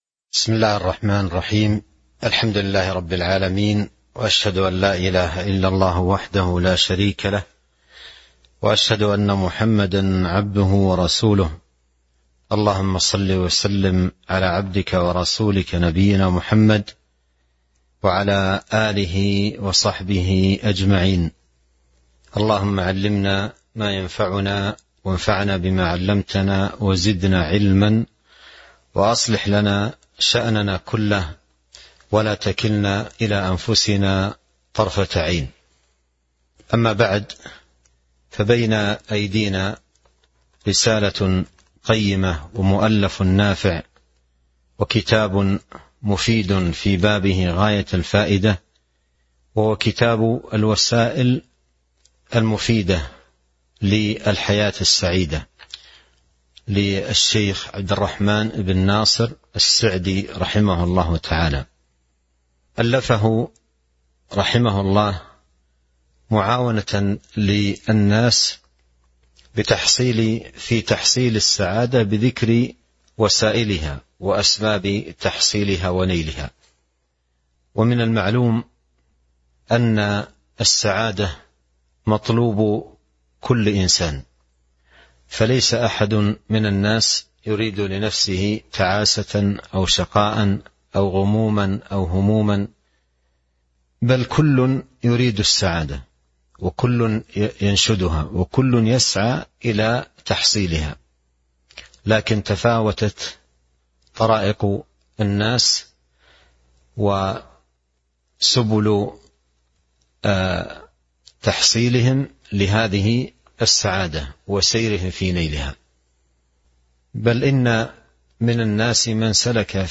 تاريخ النشر ١٣ رمضان ١٤٤٢ المكان: المسجد النبوي الشيخ